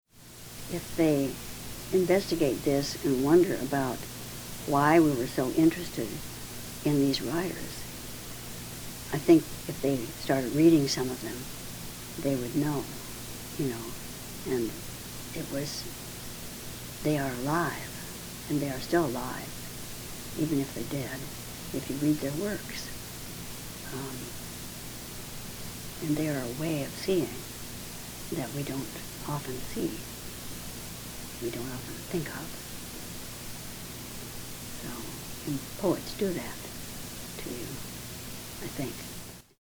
Summary of Interview
Location: Lane Community College Archives, Eugene, Oregon